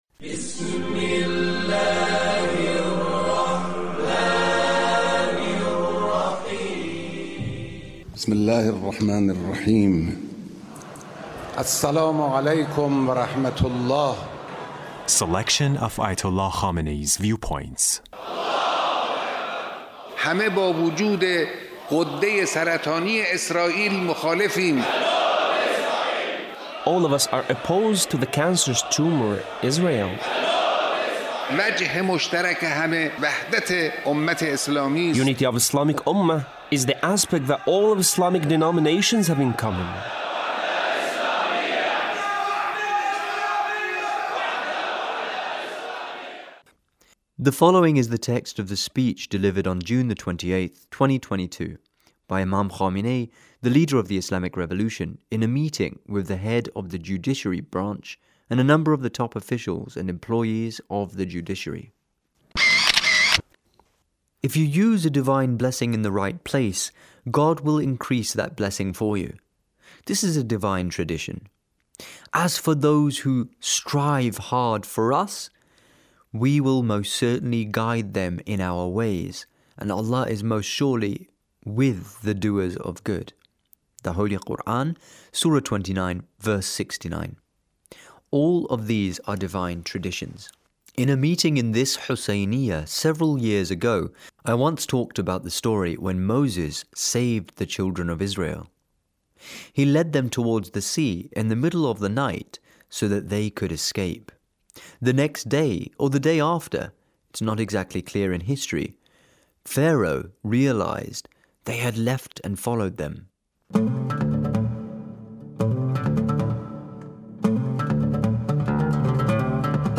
Leader's speech (1459)
The Leader's speech in a meeting with a number of the top officials and employees of the Judiciary.